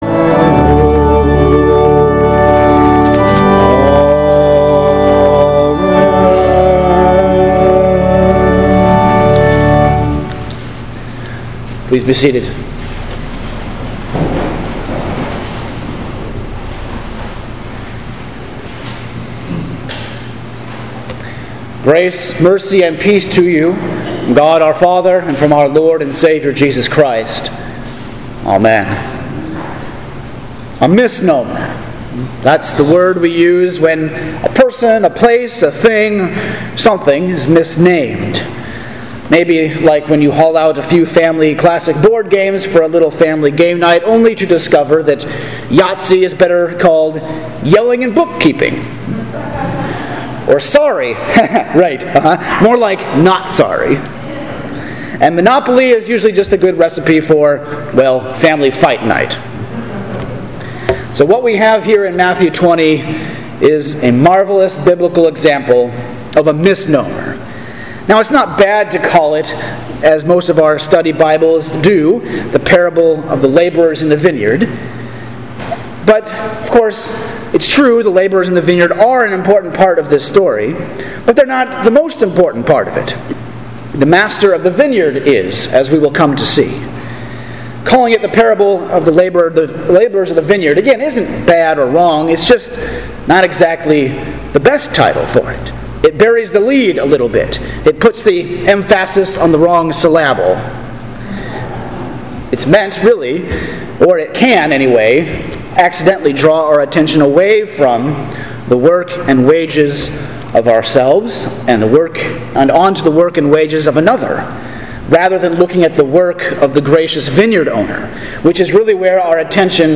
Sermon for Pentecost 16 – September 20, 2020